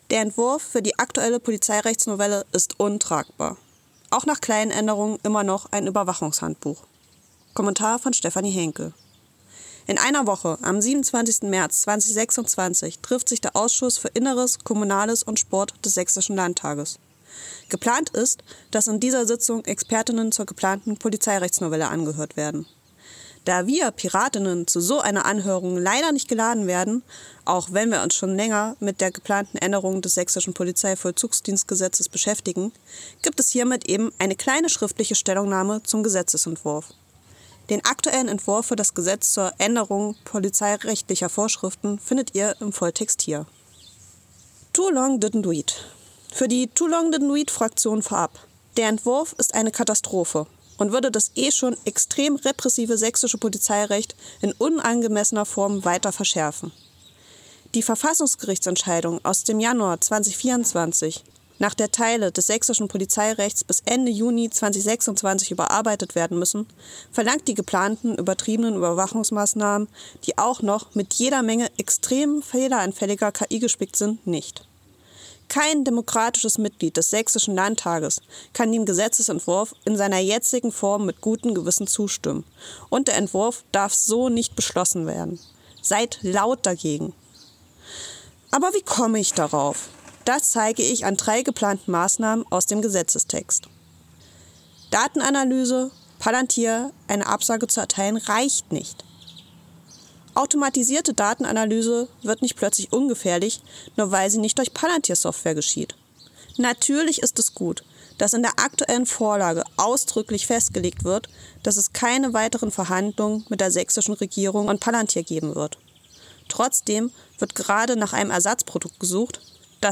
Text als Hörfassung: ▶